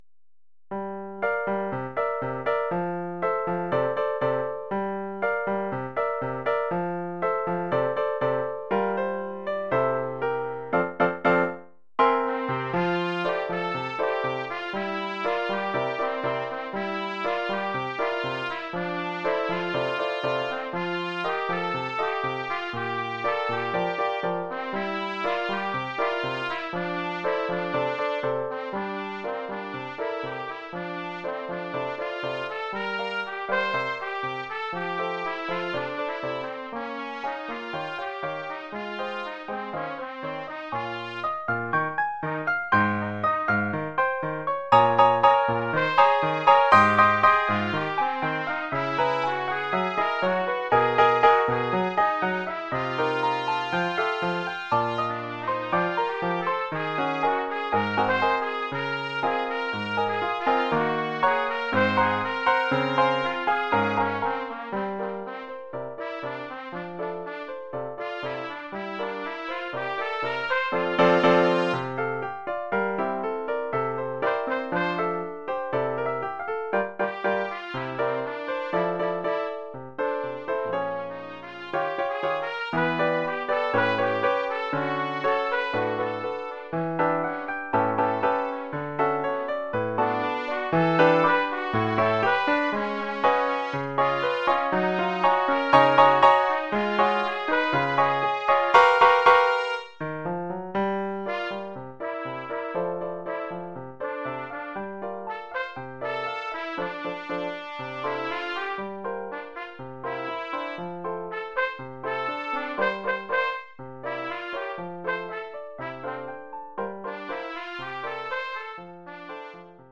Oeuvre pour trompette ou cornet ou bugle et piano.